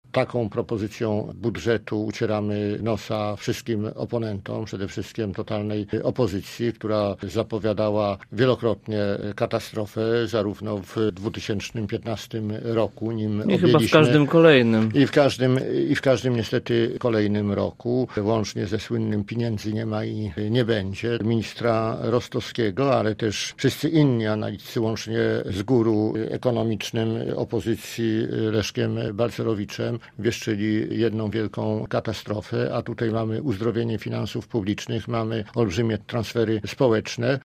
– Nie będzie deficytu – mówił w Rozmowie Punkt 9 przewodniczący lubuskich struktur Prawa i Sprawiedliwości: